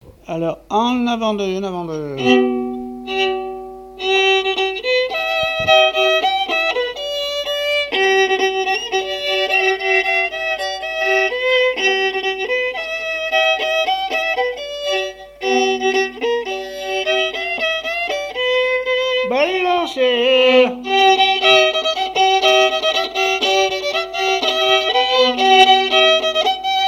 danse : quadrille : avant-deux
répertoire musical au violon
Pièce musicale inédite